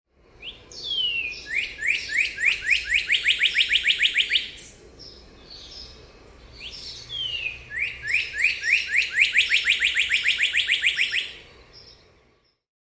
کاردینال نر یکی از زیباترین آوازها را در میان دیگر پرندگان دارد و از آن برای جلب توجه پرنده ماده در فصل جفت‌گیری استفاده می‌کند.
صدای پرنده کاردینال:
cardinal-bird-call.mp3